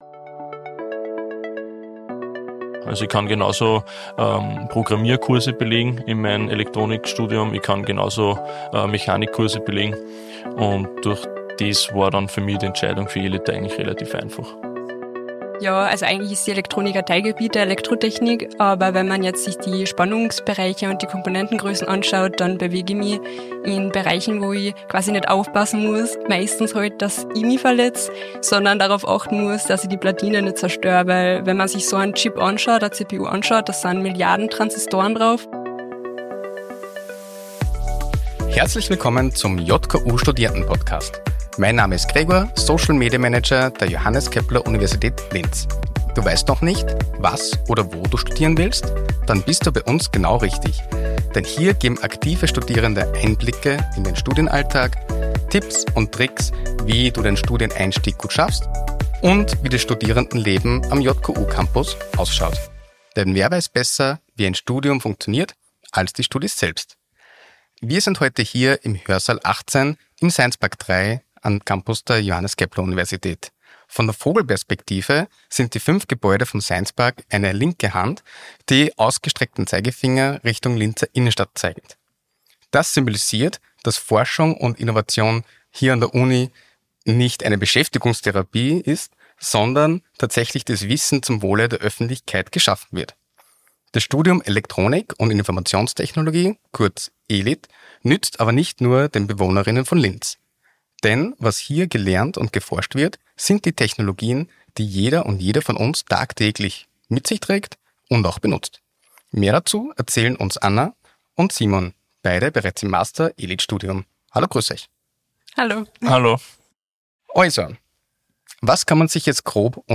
Hörsaal Einblicke - JKU Studierende im Gespräch Podcast